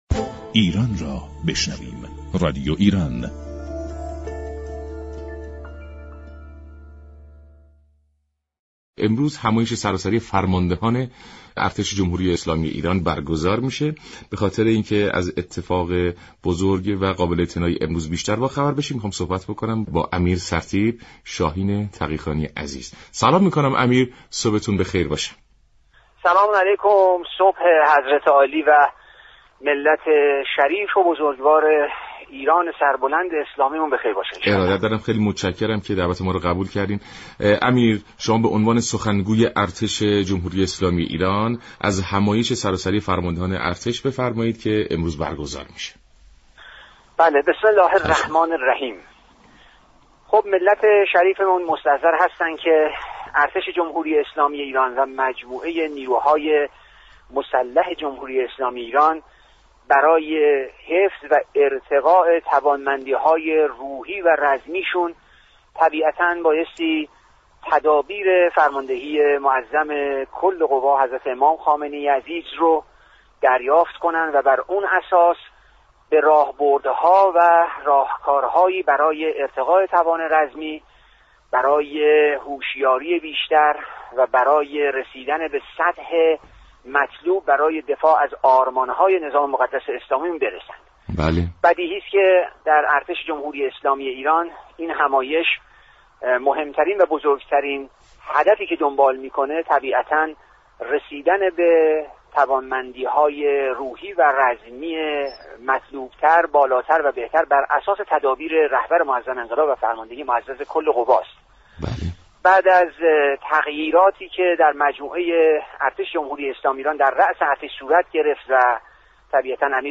امیر سرتیپ «شاهین تقی خانی» سخنگوی ارتش در گفت و گو با سلام ایران گفت: همایش سراسری ارتش در راستای راستای استكبار ستیزی و تعالی معنوی و بصیرتی است